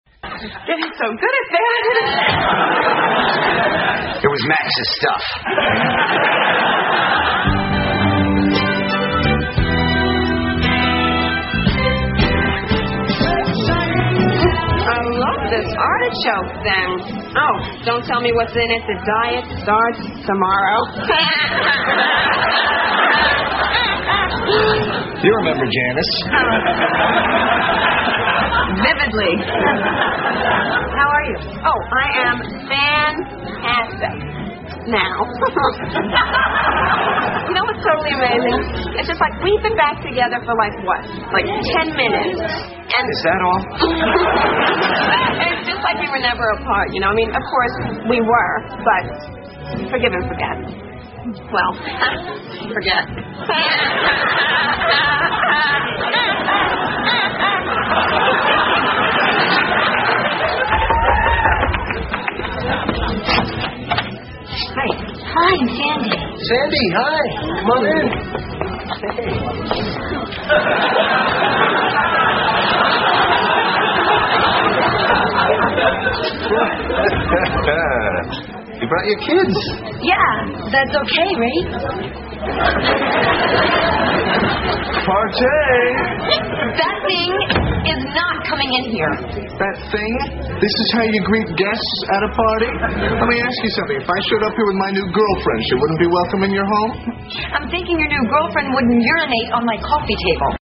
在线英语听力室老友记精校版第1季 第121期:猴子(9)的听力文件下载, 《老友记精校版》是美国乃至全世界最受欢迎的情景喜剧，一共拍摄了10季，以其幽默的对白和与现实生活的贴近吸引了无数的观众，精校版栏目搭配高音质音频与同步双语字幕，是练习提升英语听力水平，积累英语知识的好帮手。